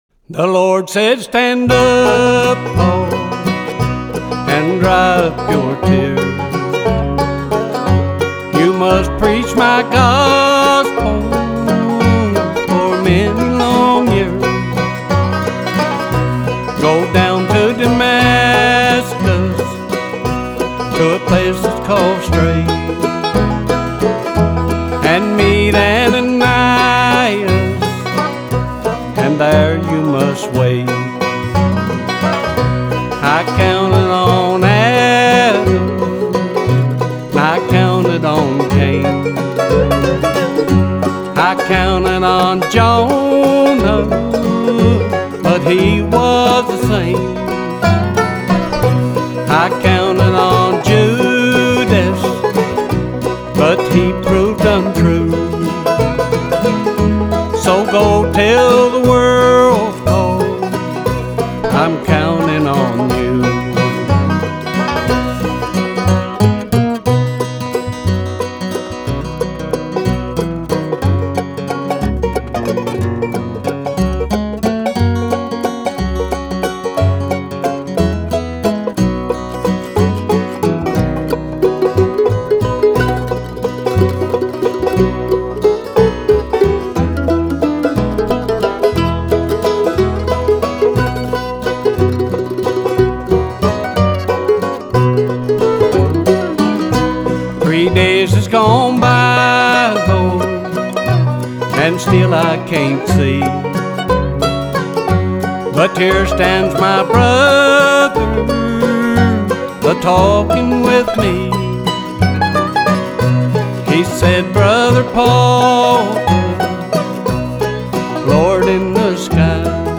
Banjo & Vocal
Mandolin & Vocal
Bass & Vocal
Guitar & Vocal